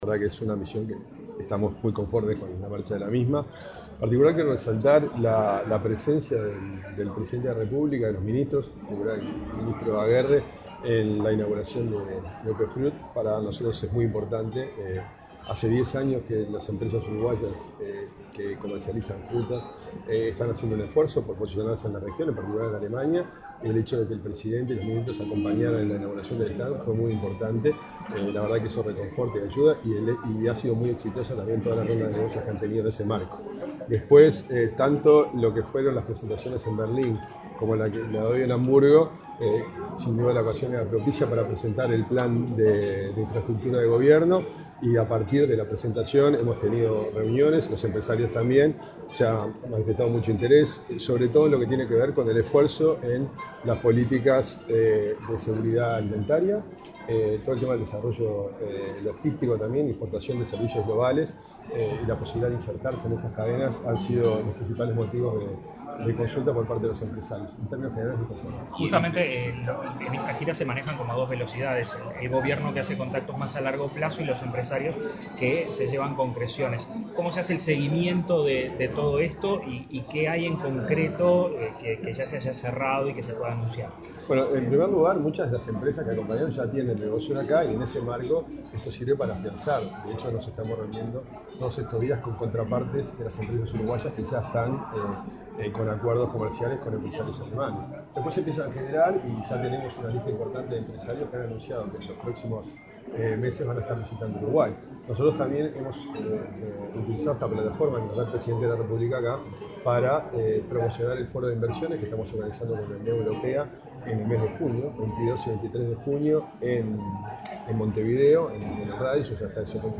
El director de Uruguay XXI, Antonio Carámbula, destacó el éxito de la visita a Alemania y los avances en temas como seguridad alimentaria e infraestructura. Dijo a la prensa que esta gira sirve para afianzar el vínculo con empresas que ya invierten en Uruguay y crear lazos con otras interesadas en futuras inversiones.